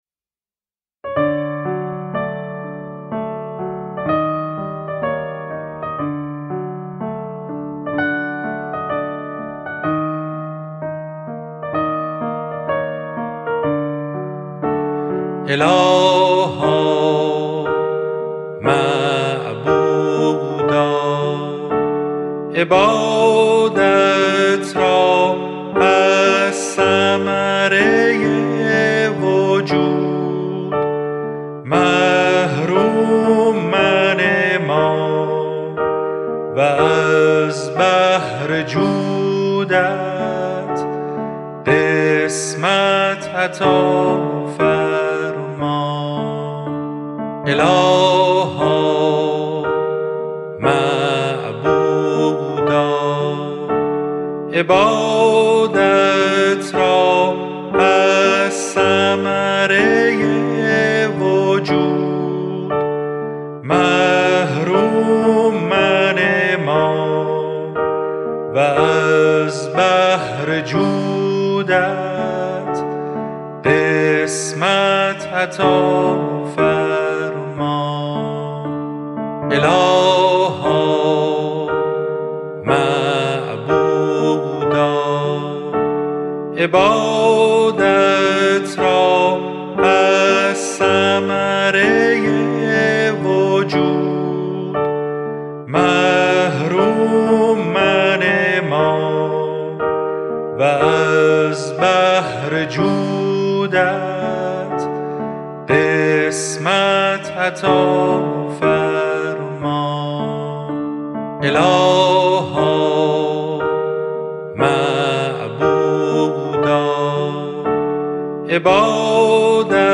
اذکار فارسی (آوازهای خوش جانان)